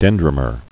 (dĕndrə-mər)